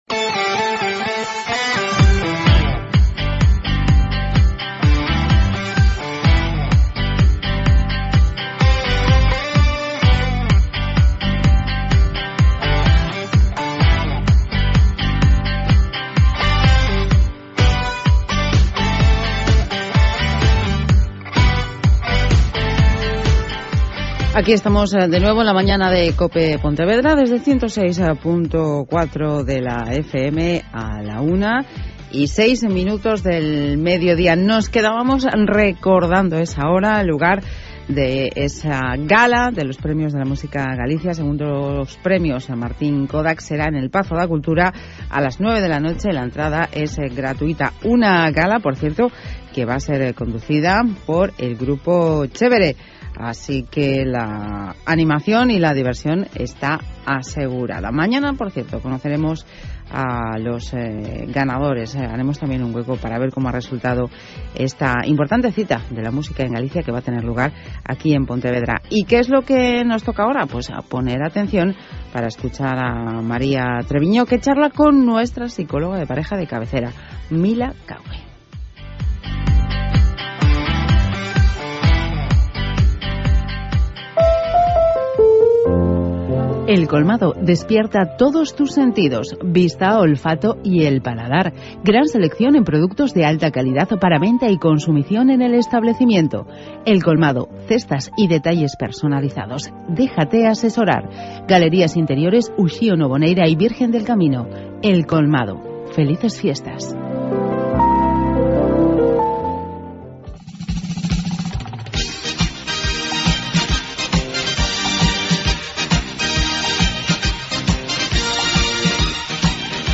Mi intervención abarca desde el minuto 2:00 hasta el minuto 11:30